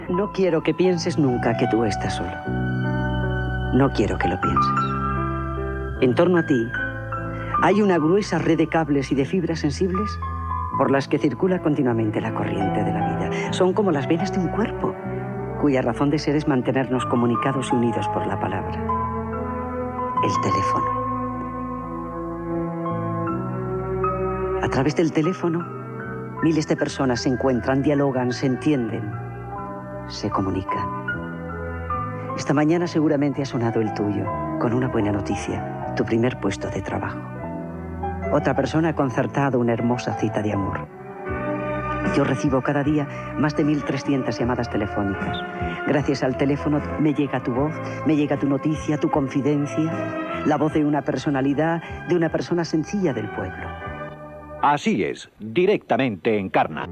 Paraules d'Encarna Sánchez sobre la possibilitat de comunicar-se per telèfon i indicatiu del programa
Entreteniment